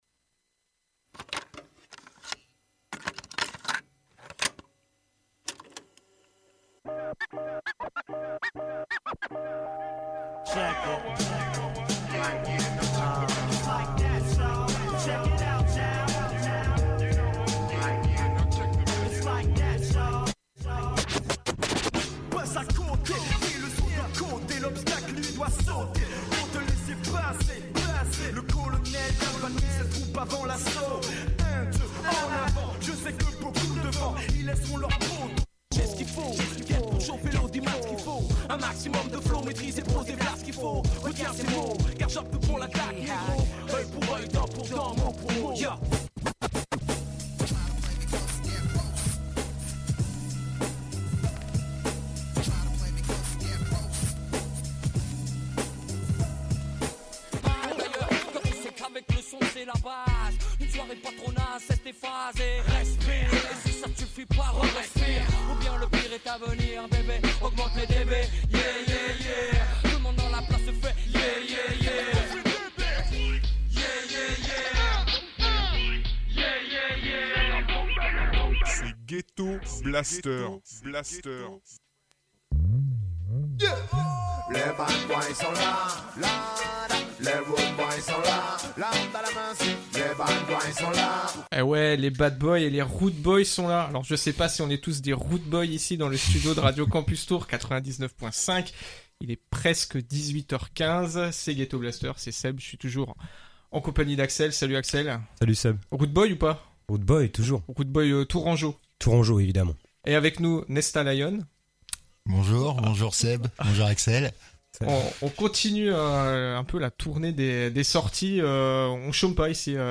Live en direct